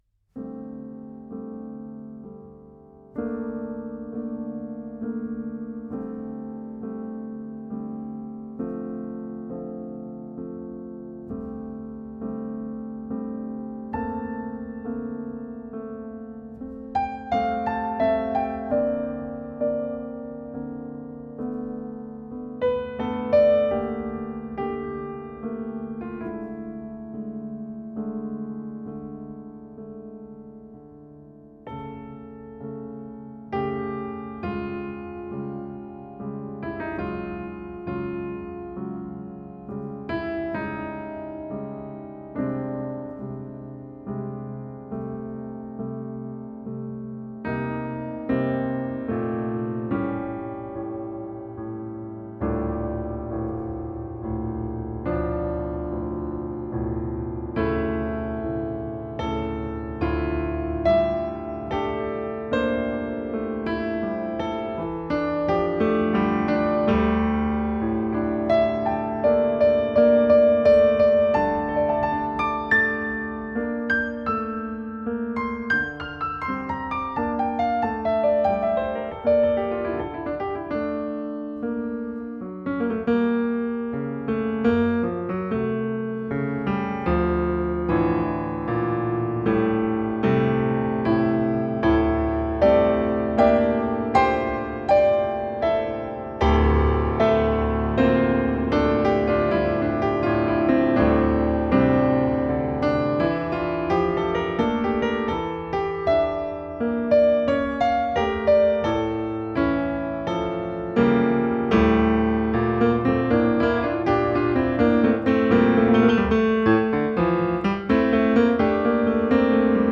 im Konzerthaus der Musikhochschule Detmold